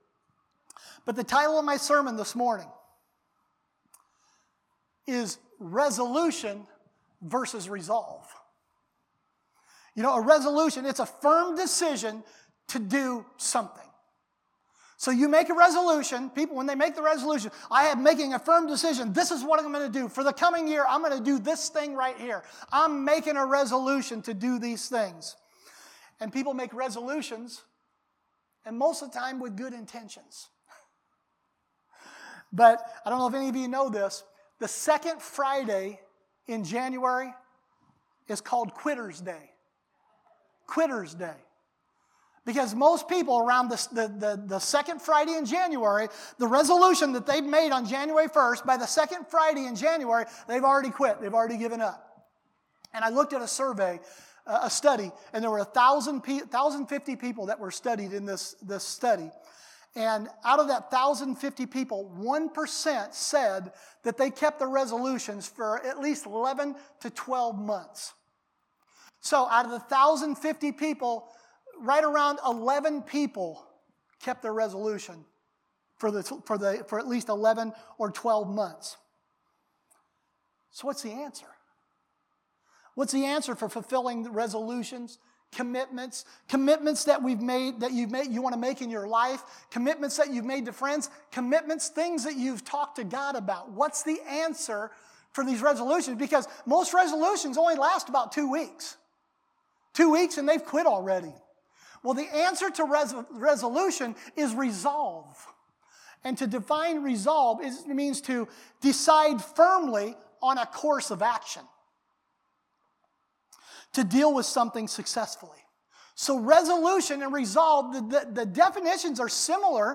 Sermons | LifePointe Church